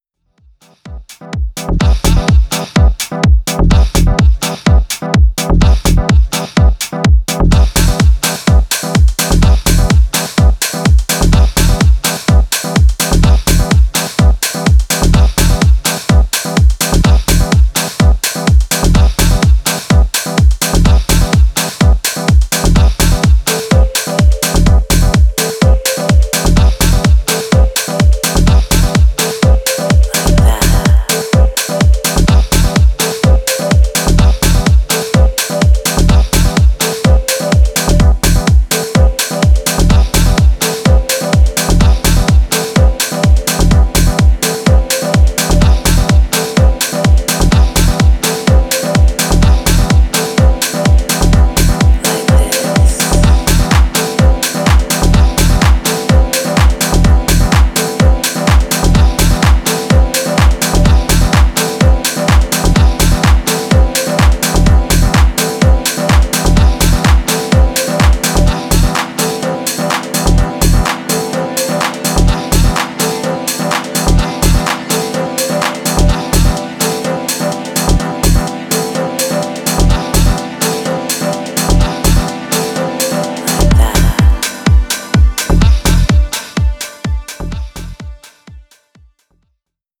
いずれからもオーセンティックな90sハウスへのリスペクトが感じられますね。